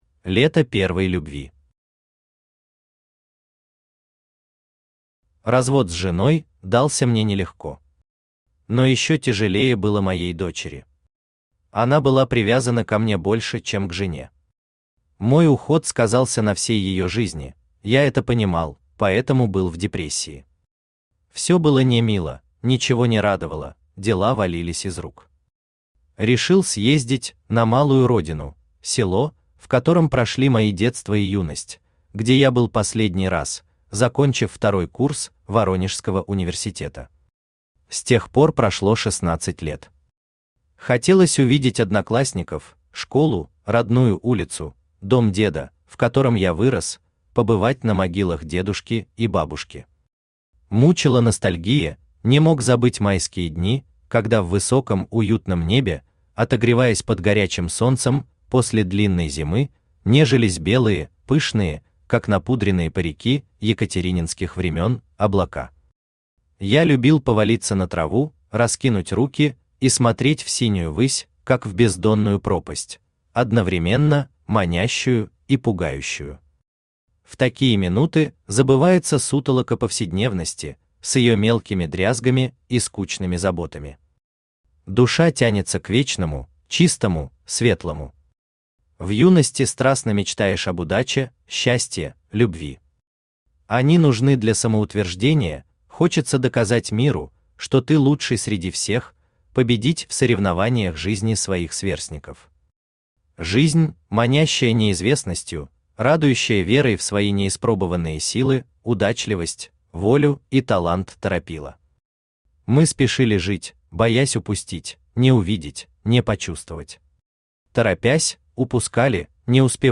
Аудиокнига Лето первой любви | Библиотека аудиокниг
Aудиокнига Лето первой любви Автор Николай Николаевич Самойлов Читает аудиокнигу Авточтец ЛитРес.